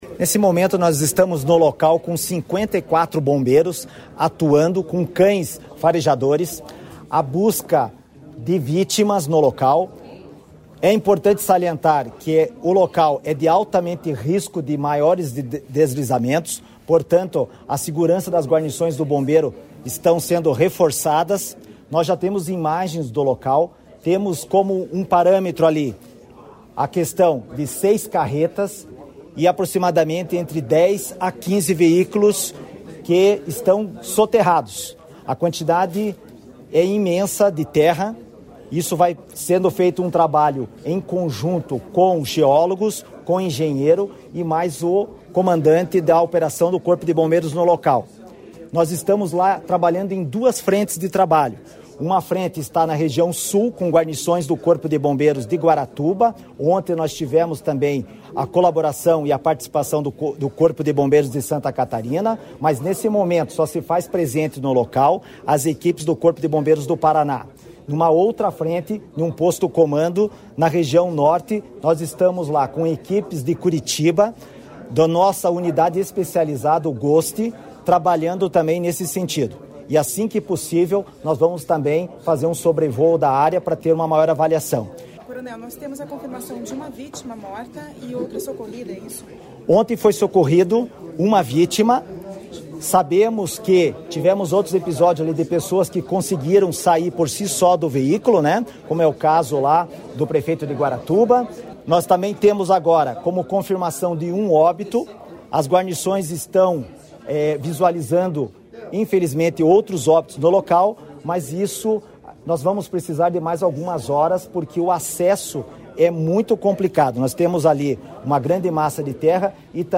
Sonora do comandante-geral do Corpo de Bombeiros do Paraná, coronel Manoel Vasco, sobre os deslizamentos de terras em rodovias do Paraná